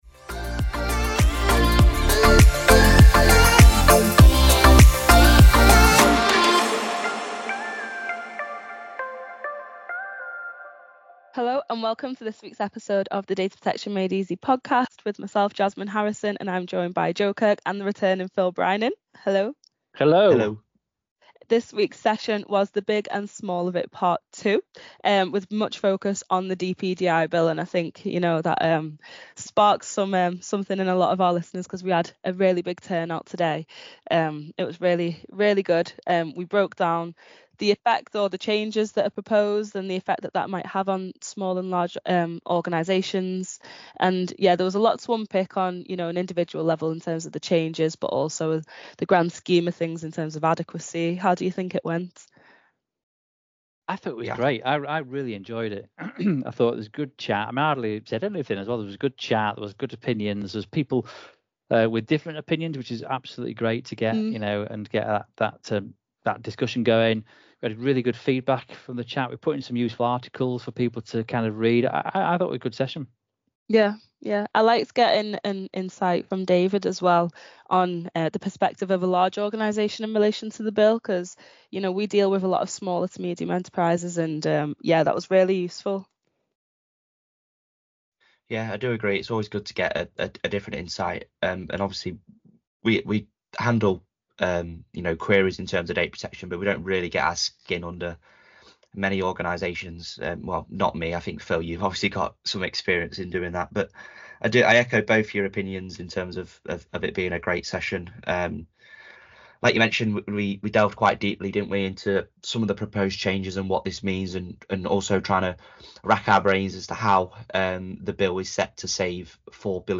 Over 120 data protection enthusiasts joined the lively discussion – a perk available to subscribers on our website!